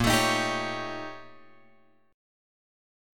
A#mM13 chord {6 x 7 8 8 9} chord